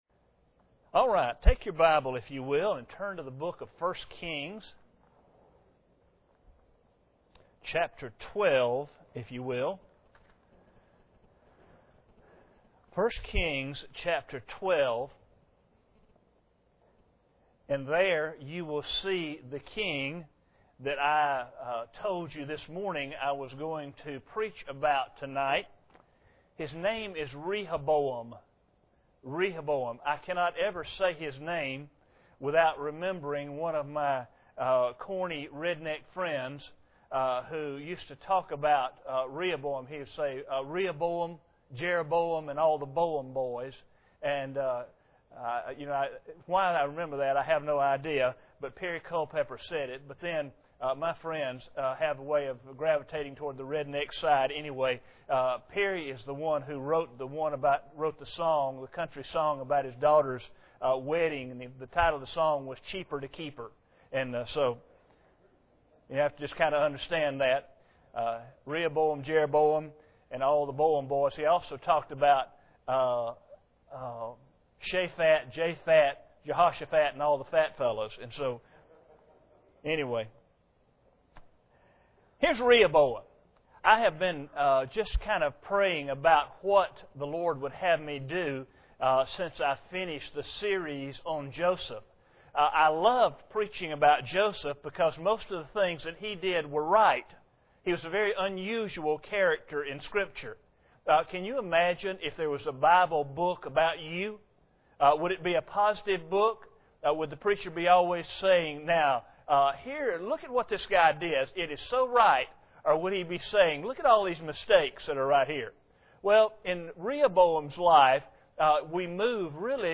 1 Kings 12:1-33 Service Type: Sunday Evening Bible Text